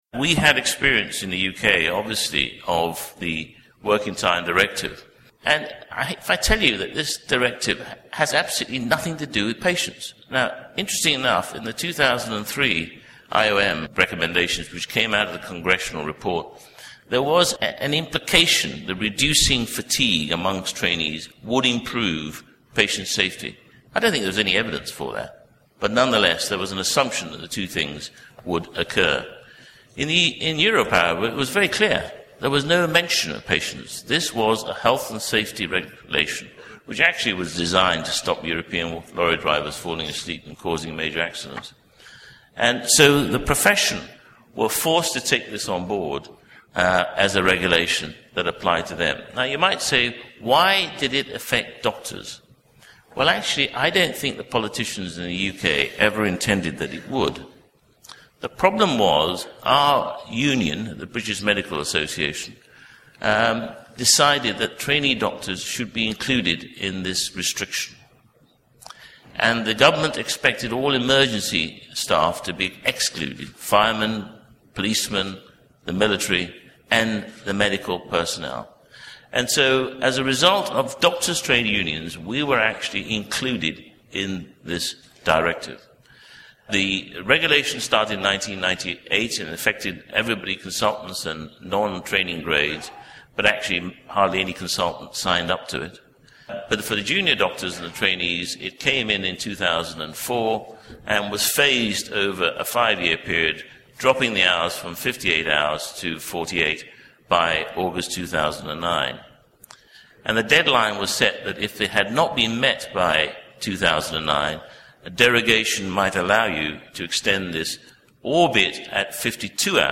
Lord Ribeiro gave his presentation at COSM 2011.
Click here to listen to a condensed version of Lord Ribeiro’s presentation.